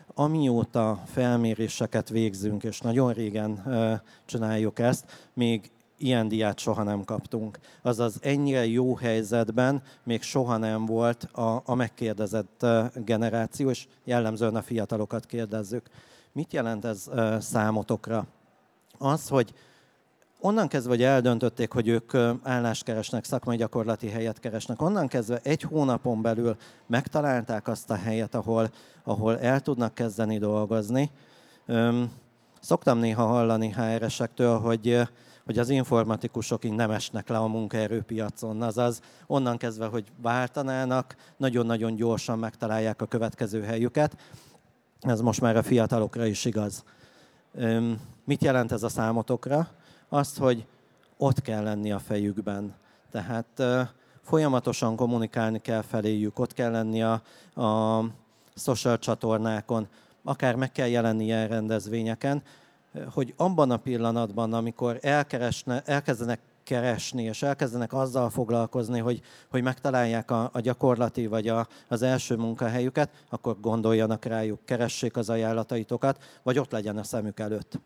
A 2023. őszi JOBVERSE Állásbörzén bepillantást engedtünk a kutatási anyagunkba!
Arra a kérdésre, hogy milyen gyorsan sikerült a jelenlegi munkájukat megszerezniük, nagyon érdekes visszajelzéseket kaptunk. Hallgass bele a JOBVERSE-en elhangzott hanganyagba